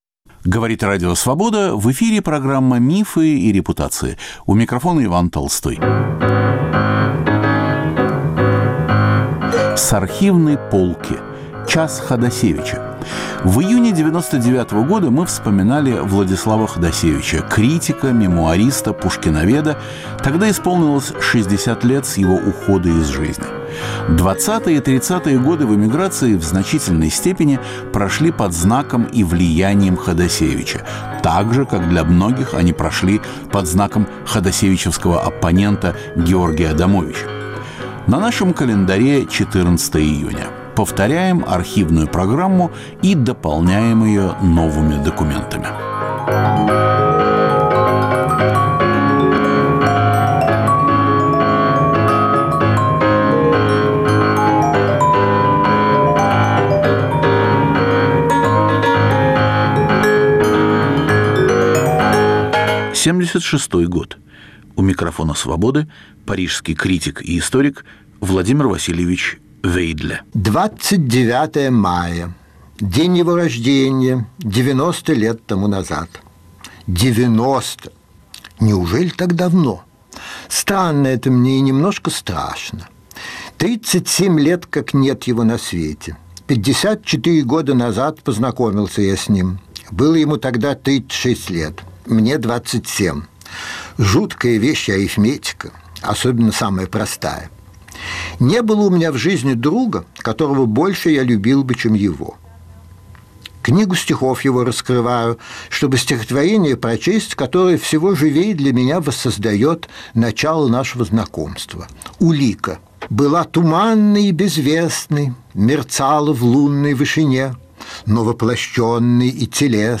Панорама архивных записей со старых пленок. О Владиславе Ходасевиче размышляют Владимир Вейдле, Александр Галич, Борис Парамонов, Нина Берберова.